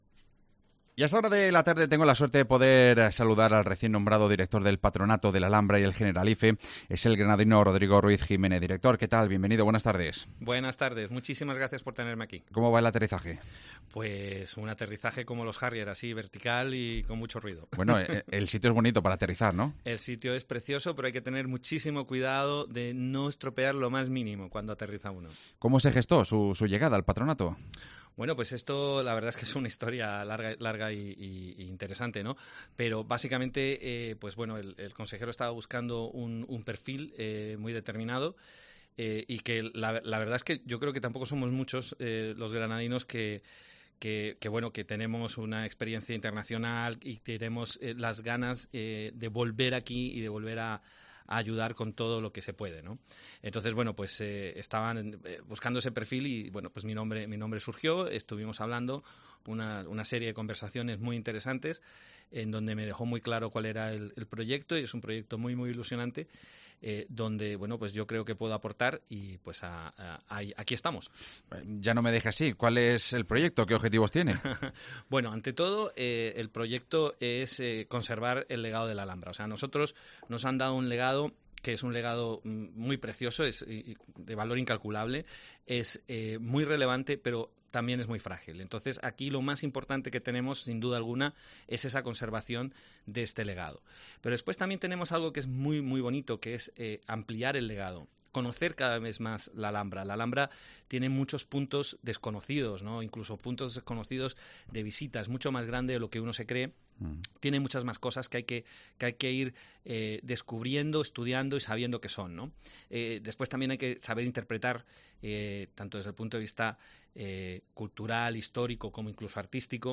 AUDIO: Entrevista al nuevo director del patronato, Rodrigo Ruiz-Jiménez